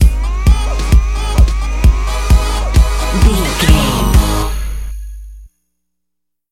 Aeolian/Minor
A♭
Fast
drum machine
synthesiser